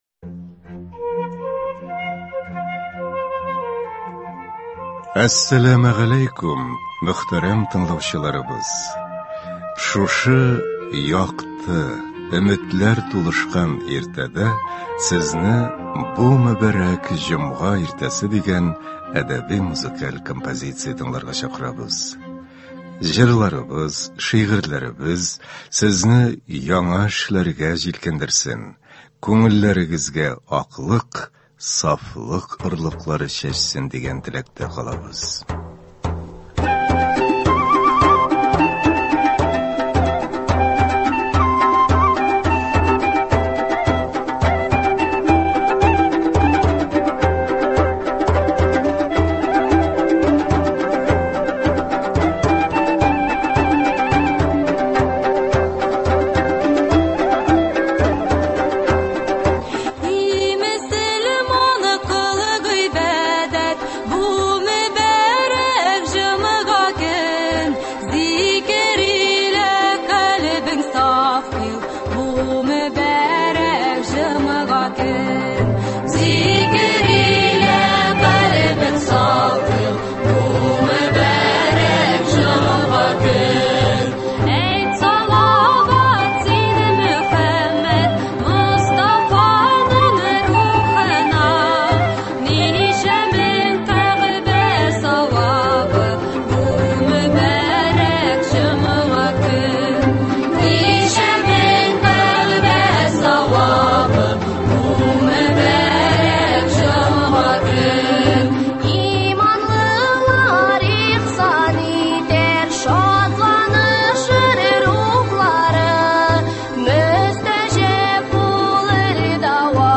Шушы якты, өметләр тулышкан иртәдә Сезне “Бу мөбарәк җомга иртәсе” дигән әдәби-музыкаль композиция тыңларга чакырабыз.